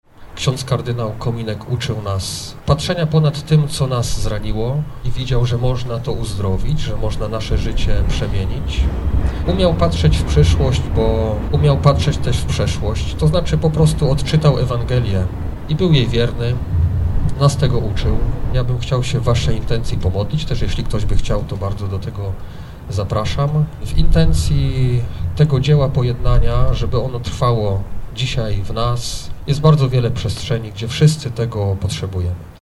–Kardynał Kominek był wielkim wizjonerem, wyprzedził swoje czasy, po prostu realizował Ewangelię. – podkreślał bp Maciej Małyga, biskup pomocniczy Archidiecezji Wrocławskiej.